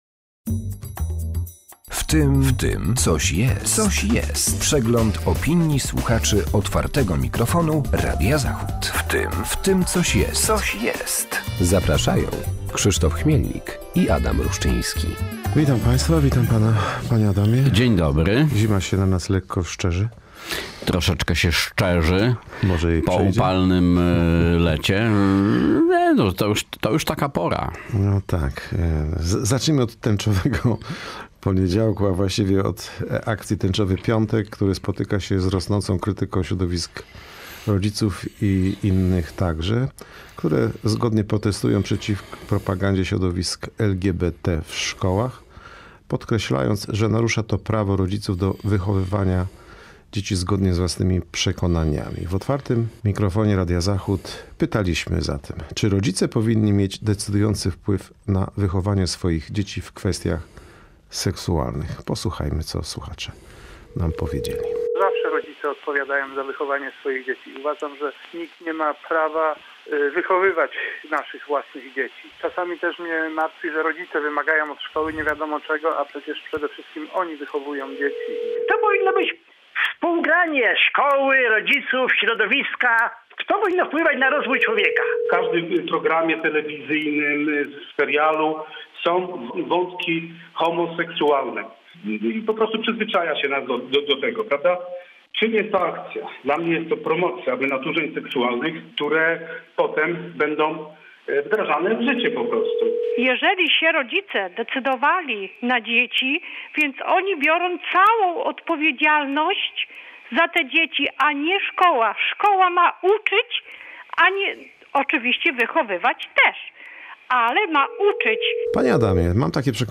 W cotygodniowej audycji przypominamy głosy słuchaczy Otwartego Mikrofonu oraz komentujemy tematy z mijającego tygodnia.